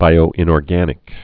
(bīō-ĭnôr-gănĭk)